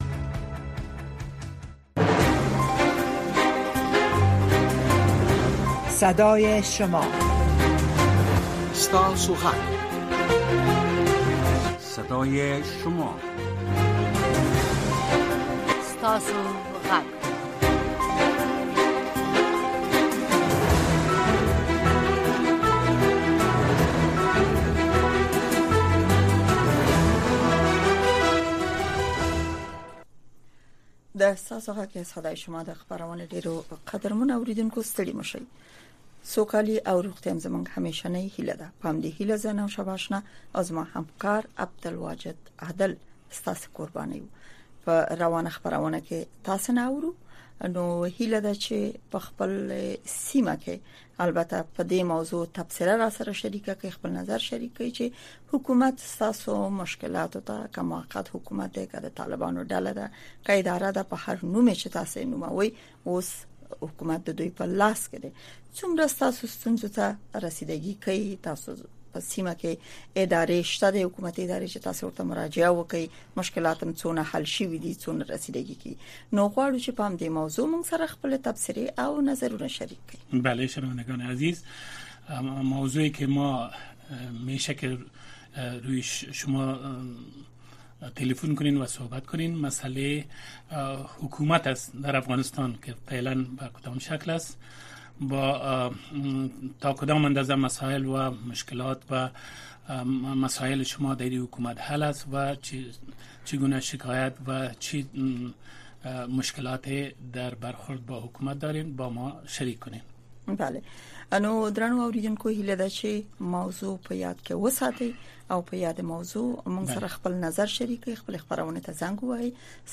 در برنامۀ صدای شما شنوندگان رادیو آشنا صدای امریکا به گونۀ مستقیم با ما به تماس شده و نظریات، نگرانی‌ها، دیدگاه، انتقادات و شکایات شان را با گردانندگان و شنوندگان این برنامه در میان می‌گذارند. این برنامه به گونۀ زنده از ساعت ۱۰:۰۰ تا ۱۰:۳۰ شب به وقت افغانستان نشر می‌شود.